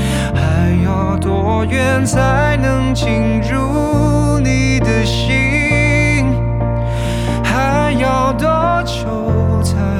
pop_cn.wav